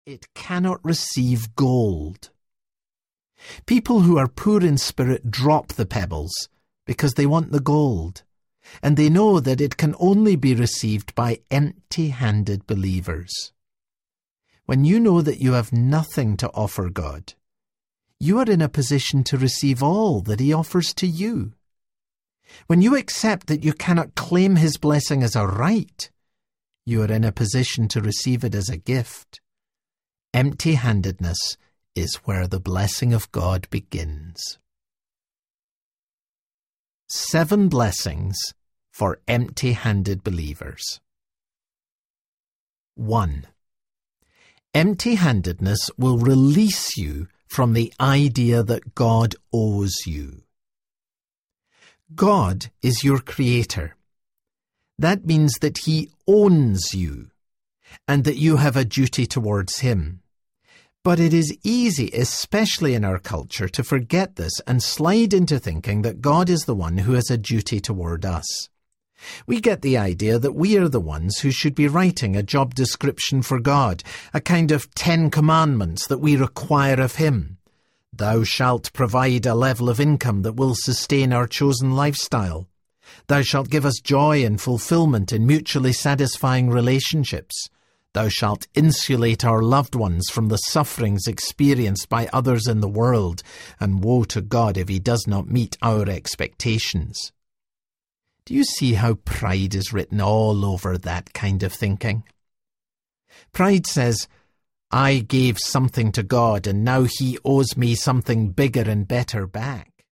Momentum Audiobook
6.58 Hrs. – Unabridged